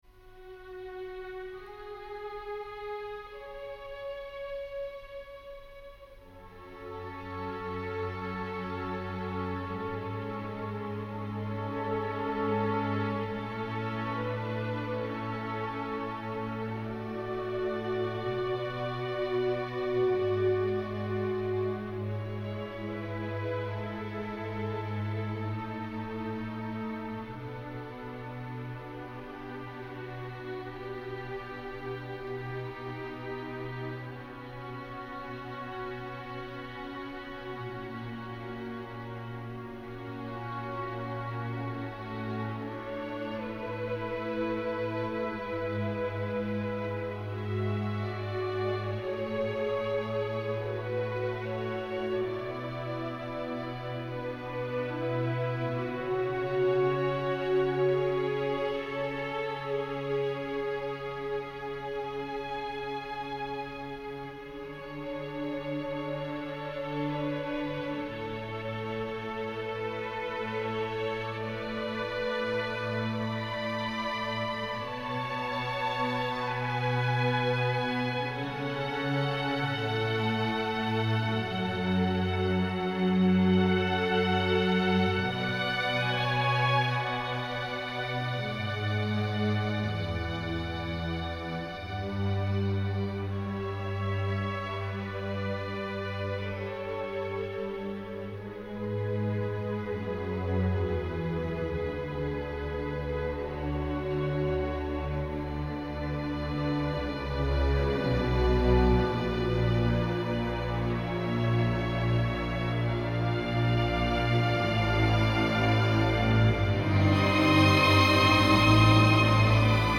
1. The strings are amazing. They soar and pulse and roll and sing and fade.
2. From the midst of all the soaring and pulsing, the harp and flutes poke their heads out at 3:32.
3. It’s unpredictable, but still melodic.